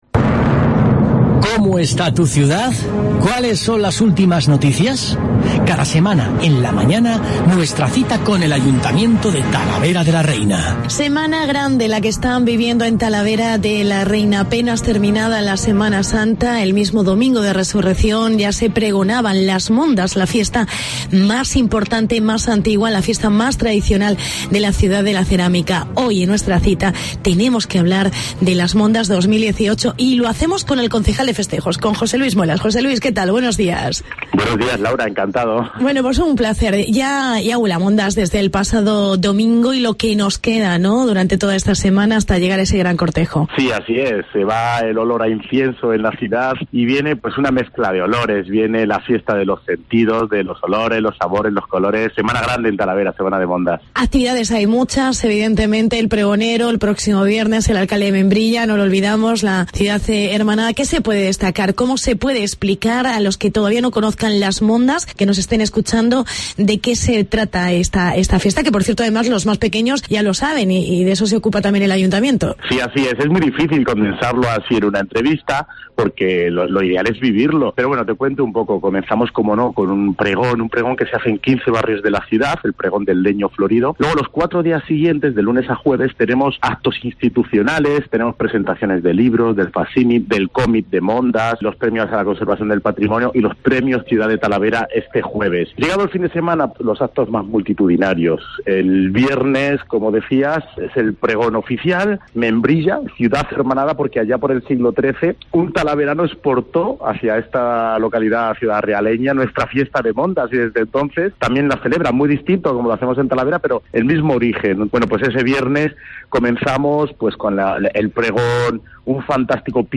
Entrevista al concejal José Luis Muelas